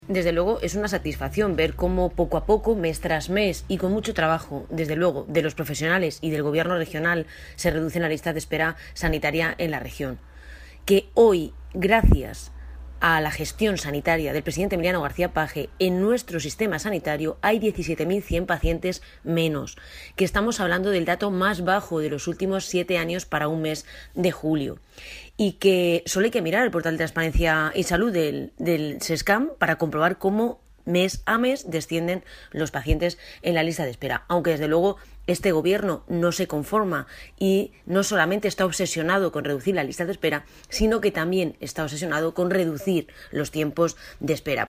La diputada del Grupo Parlamentario Socialista en las Cortes de Castilla-La Mancha, Ana Isabel Abengózar, ha mostrado su satisfacción porque el "nuevo descenso en las listas de espera sanitarias" durante el pasado mes de julio.
Cortes de audio de la rueda de prensa